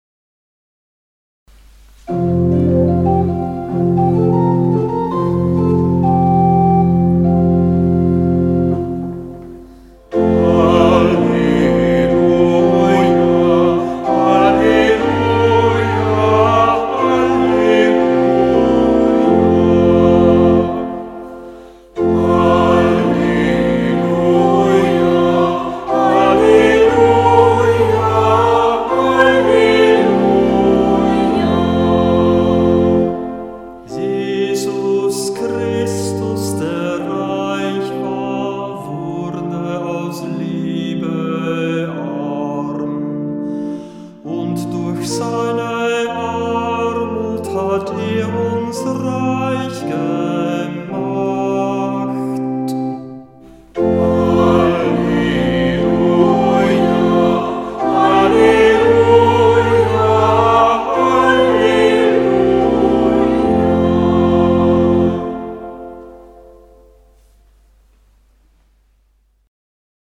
Ruf vor dem Evangelium - September 2025